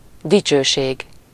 Ääntäminen
IPA : /ˈfeɪm/